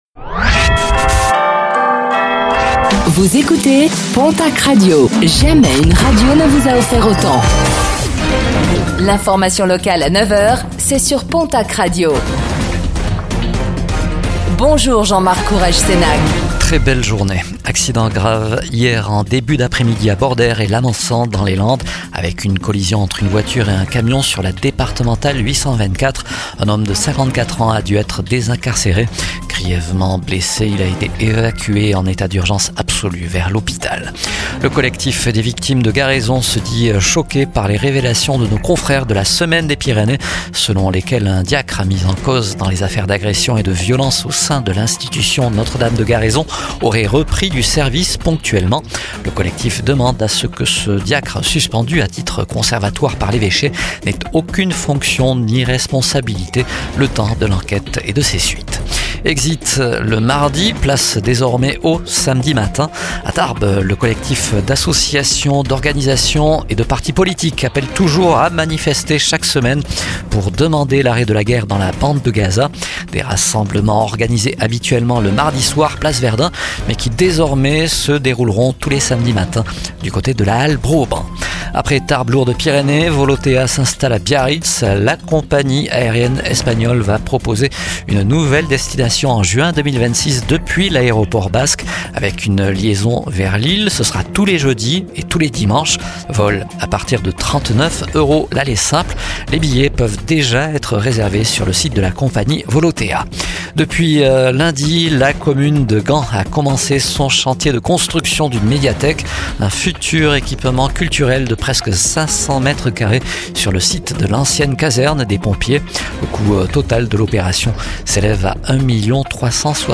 09:05 Écouter le podcast Télécharger le podcast Réécoutez le flash d'information locale de ce jeudi 30 octobre 2025